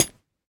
25w18a / assets / minecraft / sounds / block / chain / step2.ogg
step2.ogg